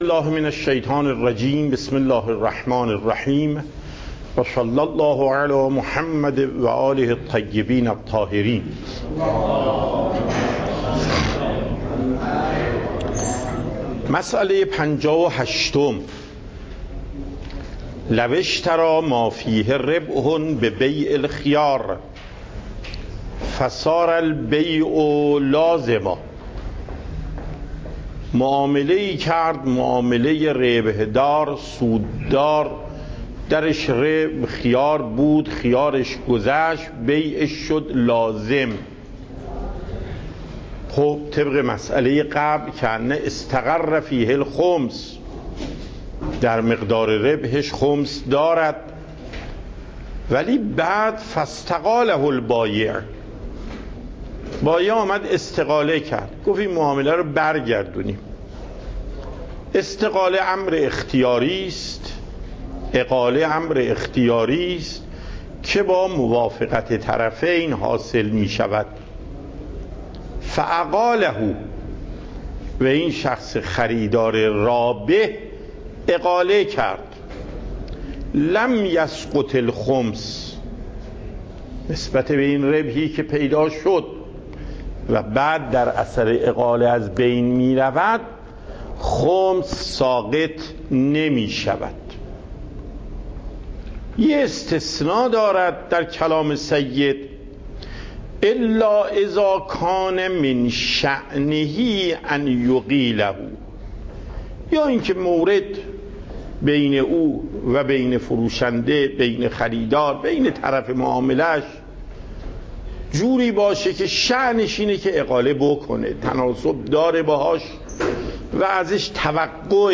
صوت و تقریر درس پخش صوت درس: متن تقریر درس: ↓↓↓ تقریری ثبت نشده است.